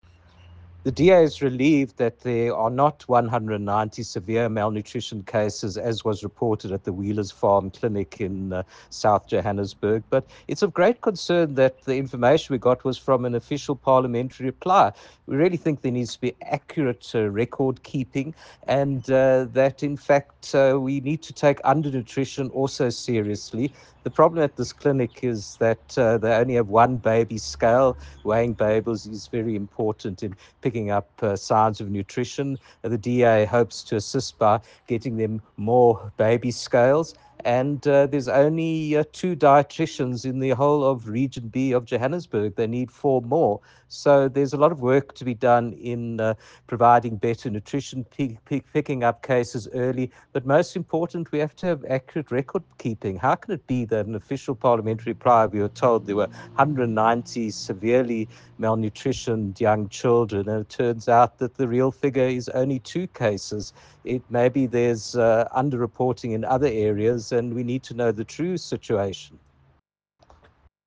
soundbite by Dr Jack Bloom.